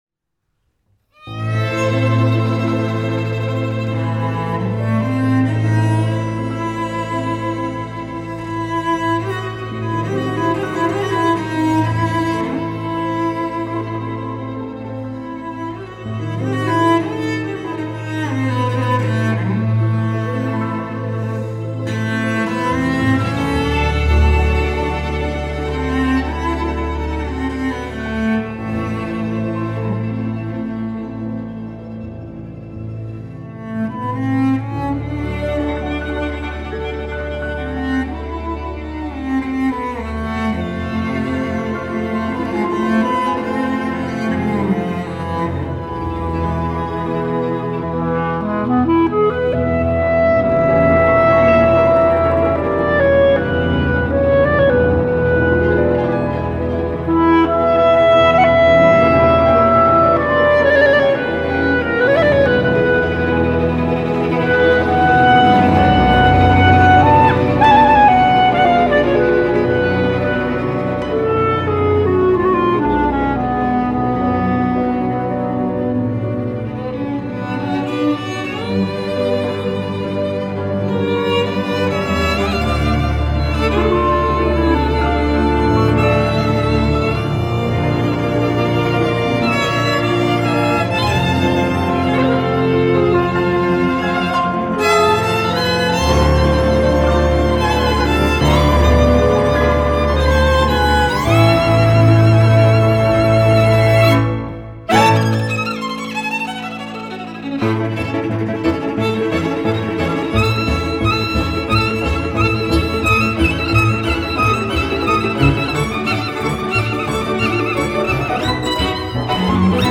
An outstanding journey into klezmer and gipsy music!